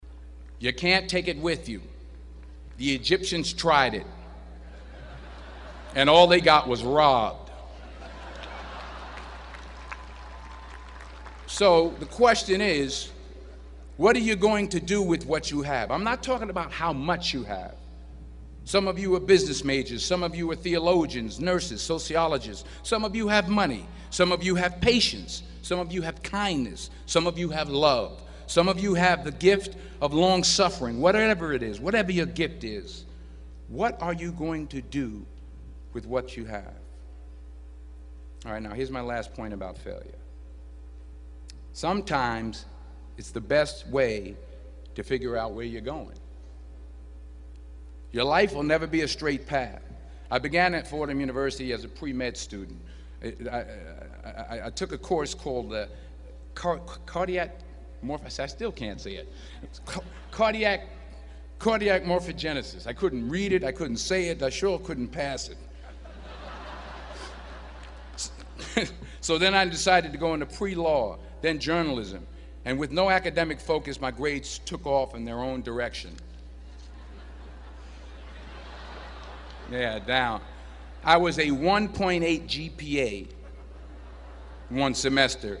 公众人物毕业演讲第428期:丹泽尔2011宾夕法尼亚大学(12) 听力文件下载—在线英语听力室